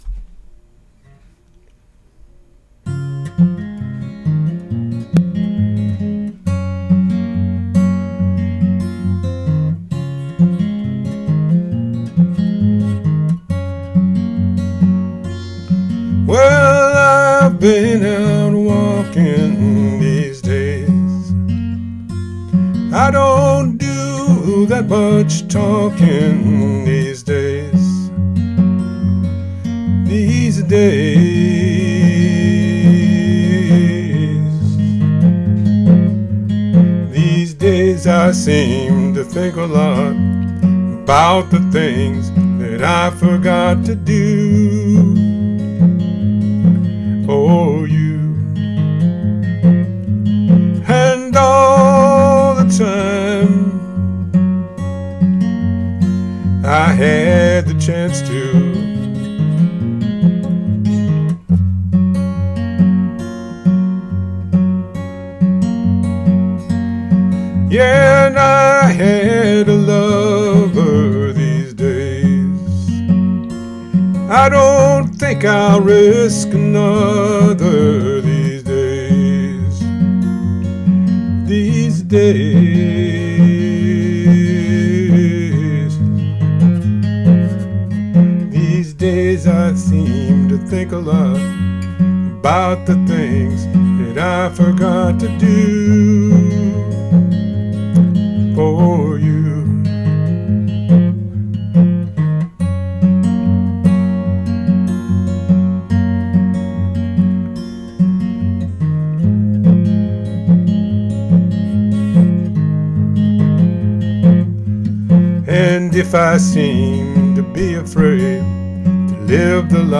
Here's my cover.